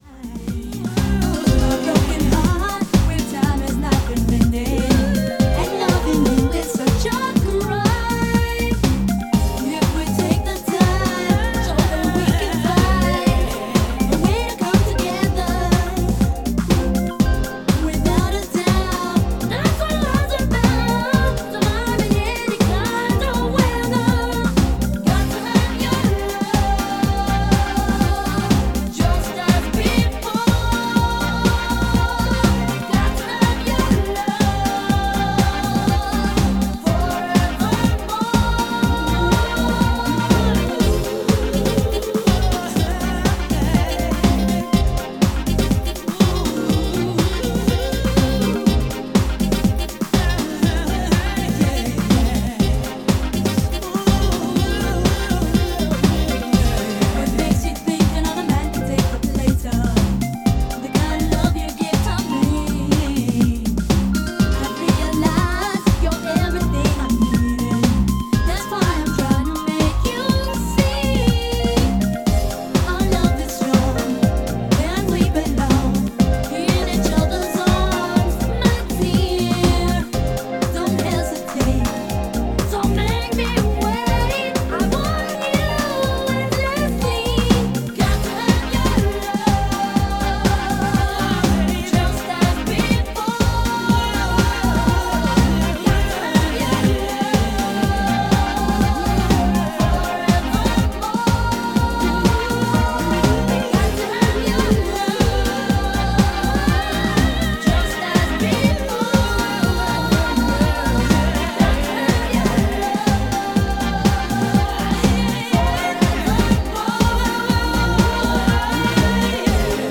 STYLE House